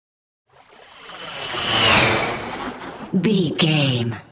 Whoosh bright
Sound Effects
bright
futuristic
high tech
whoosh